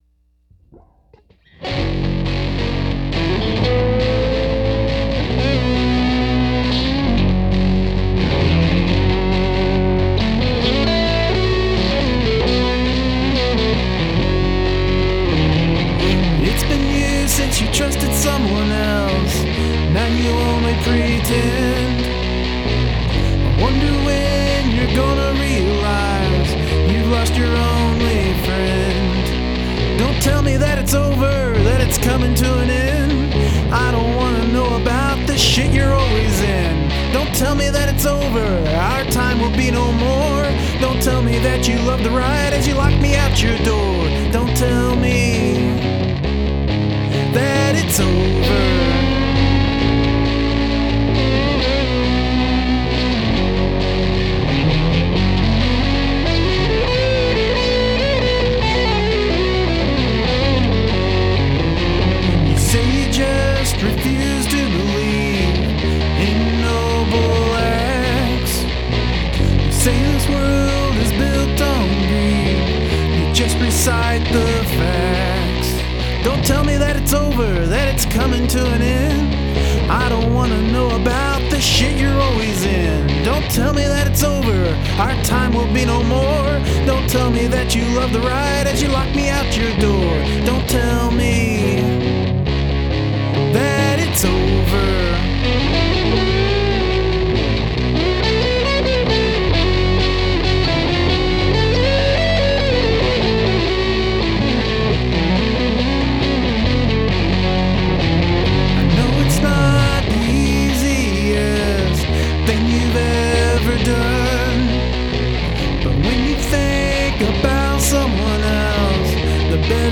Recorded on: BR900CD
All guitars: Johnson Strat Copy
Bass: Squire P-Bass
Vox: AT2020
I was over halfway through before I realised there were no drums :D. It made me listen to it again as soon as it finished.
The guitars work really well on this, the solos going on behind that raw rhythm really complement the lyrics.
great american rock . and roll
That guitar is brilliantly grungy. Your voice is in fine form, with some anger and bite to it.